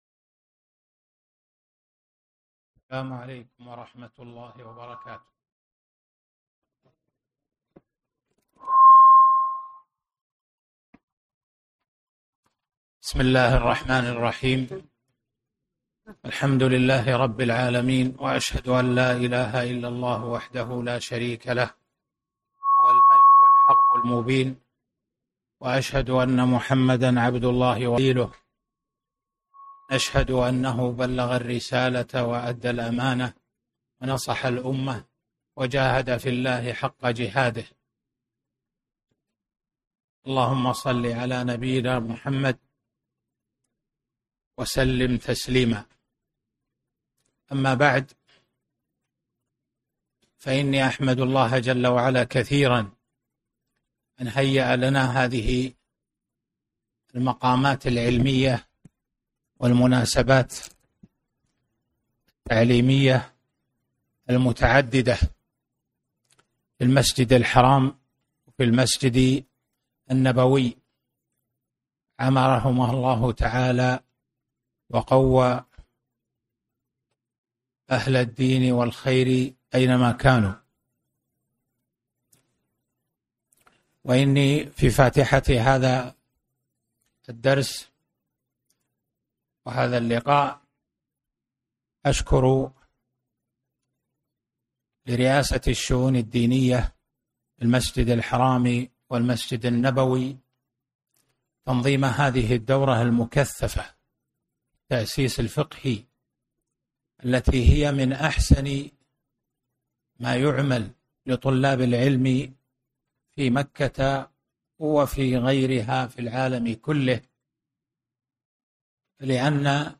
محاضرة قيمة - تكوين الملكة الفقهية